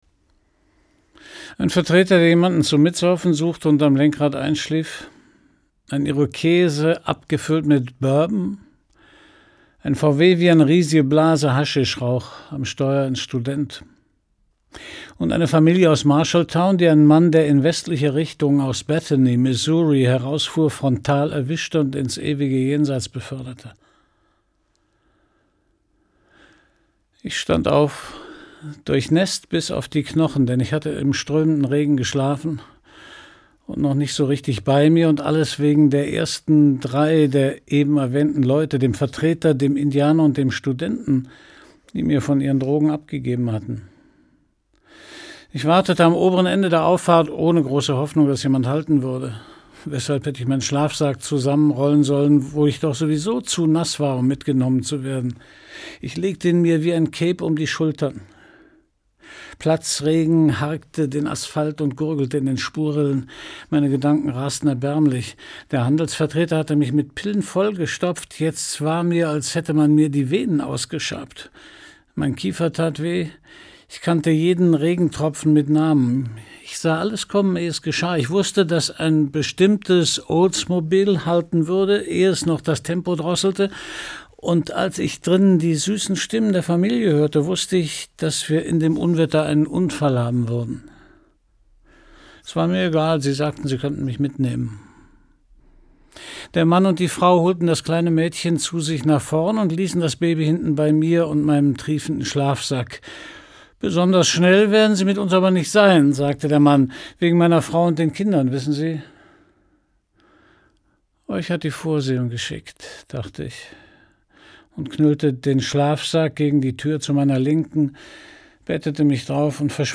Gekürzt Autorisierte, d.h. von Autor:innen und / oder Verlagen freigegebene, bearbeitete Fassung.
Jesus' Sohn Gelesen von: Christian Brückner